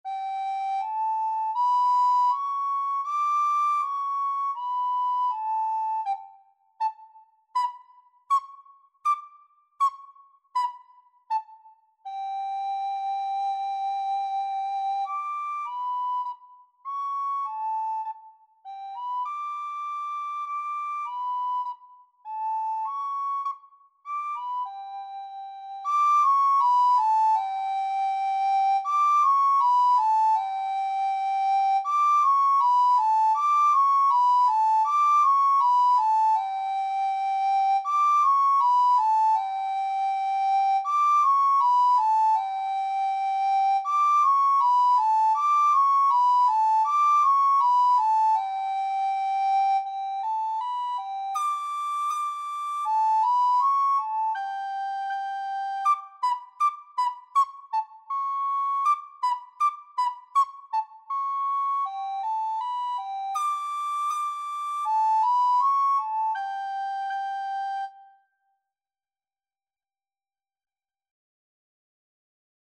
Free Sheet music for Soprano (Descant) Recorder
4/4 (View more 4/4 Music)
G6-D7
Beginners Level: Recommended for Beginners
Classical (View more Classical Recorder Music)